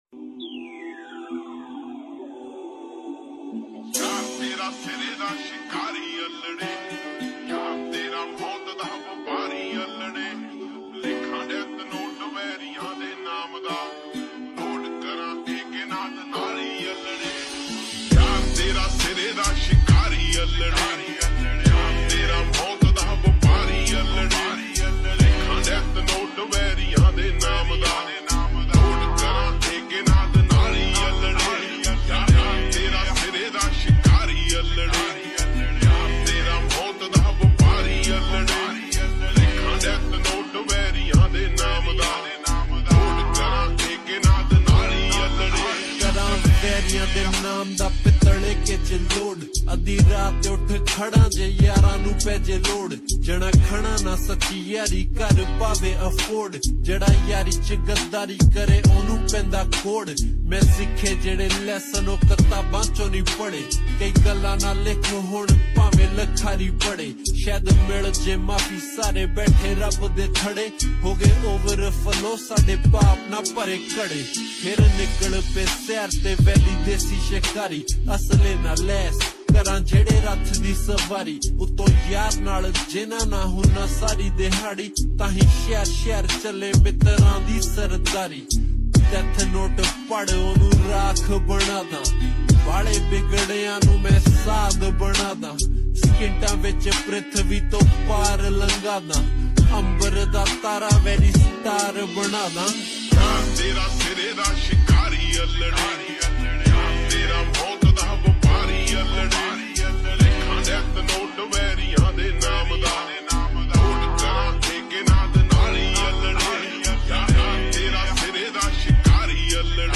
Full song slow x reverb